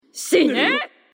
PLAY OROCHI SHINE FEMALE
for-honor-orochi-shine-female.mp3